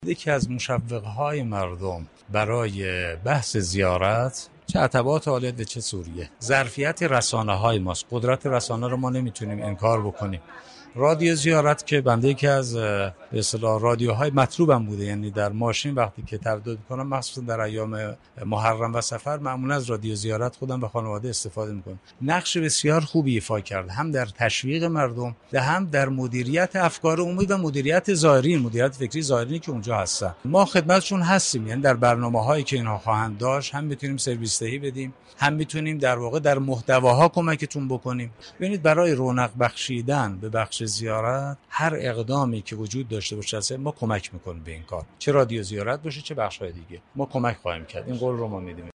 به گزارش رادیو زیارت، سید صادق حسینی در گفتگوی اختصاصی با این رادیو افزود: یکی از مشوق های مردم در خصوص بحث زیارت قدرت رسانه است.